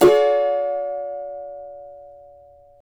CAVA D#MJ  U.wav